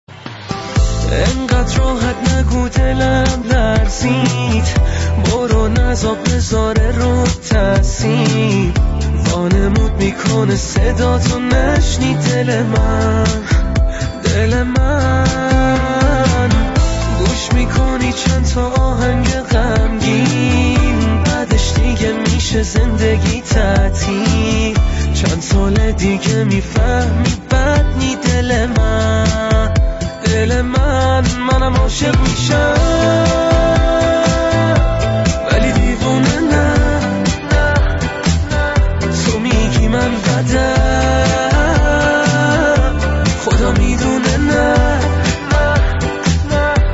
آهنگ زنگ غمگین